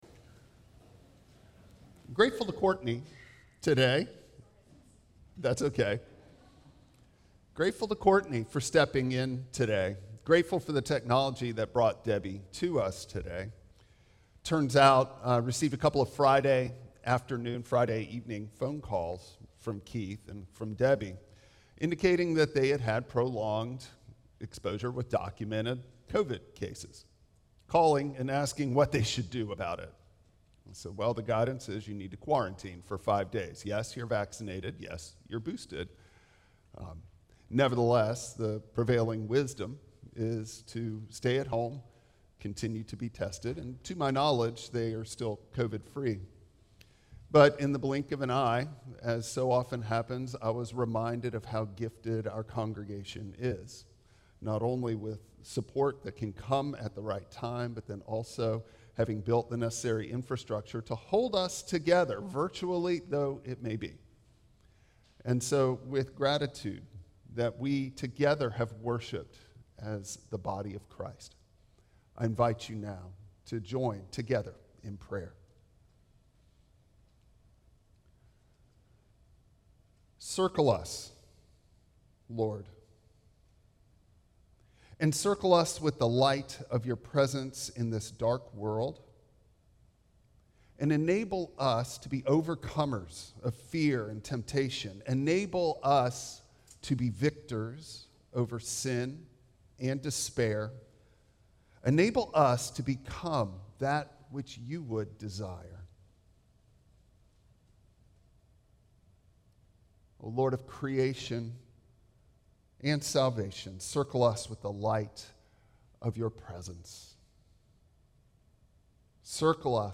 Passage: Isaiah 6:1-8 Service Type: Traditional Service Bible Text